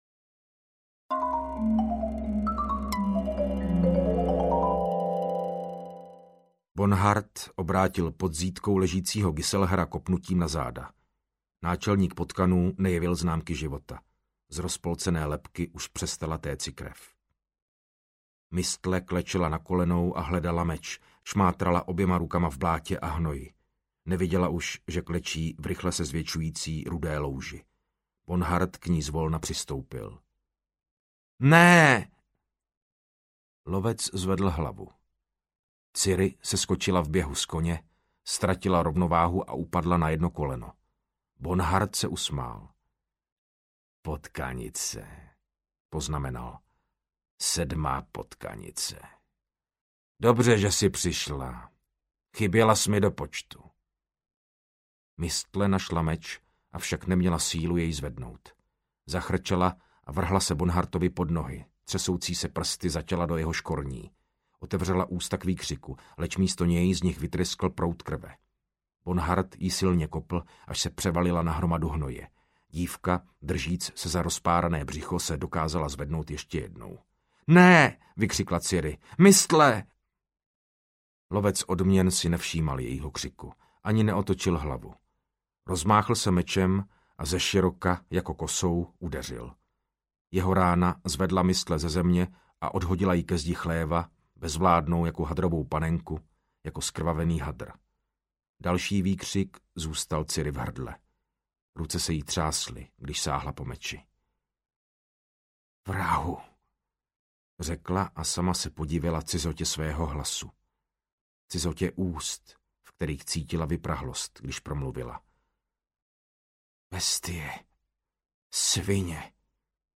Věž vlaštovky audiokniha
Ukázka z knihy
• InterpretMartin Finger